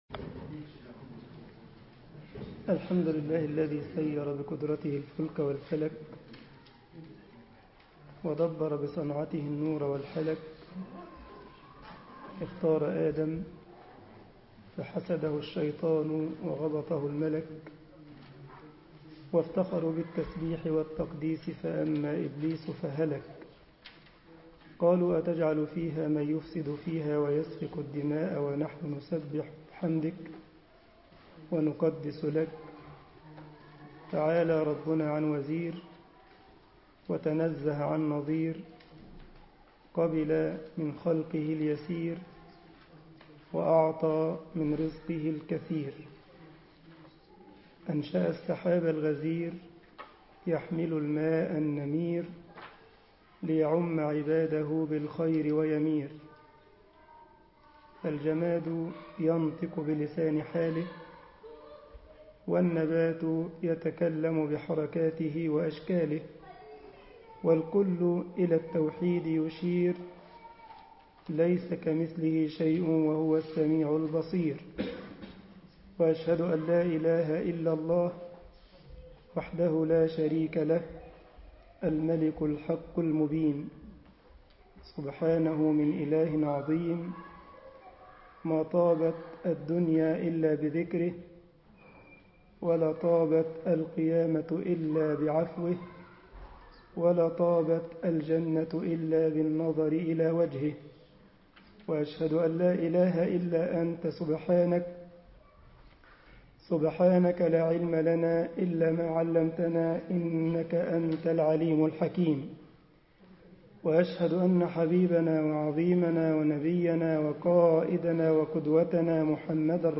مسجد الجمعية الاسلامية بالسارلند محاضرة